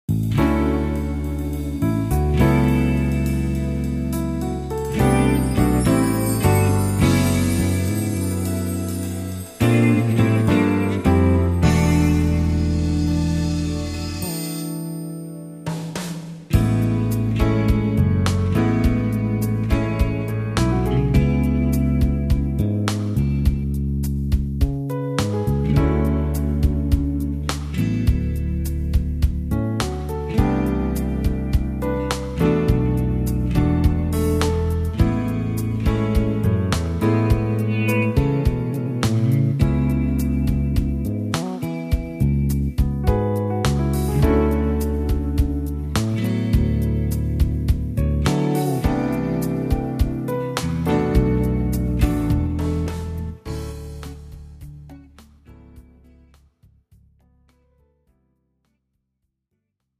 미리듣기
장르 pop 구분